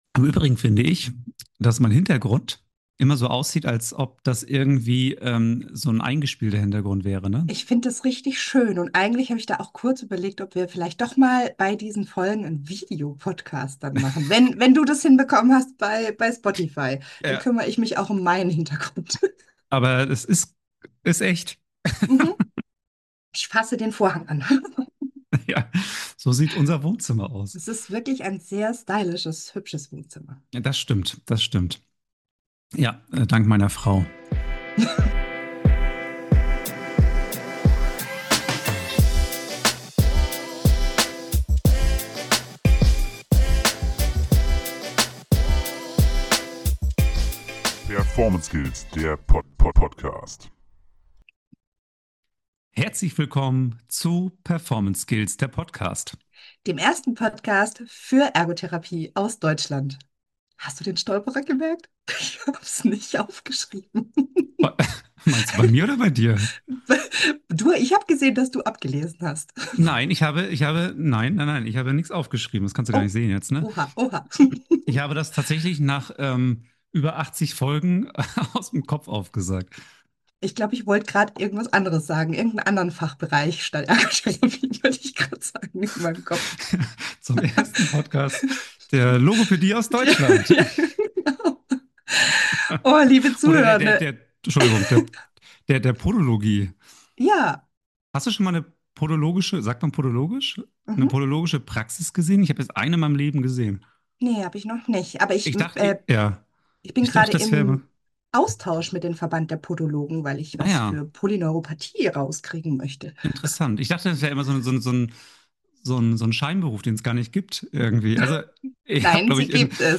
Besonders spannend: Es gibt kein Skript, nichts ist vorbereitet. In der ersten Folge von Performance Talks geht es um den letzten „Ergomoment“ und die Frage des funktionellen Trainings.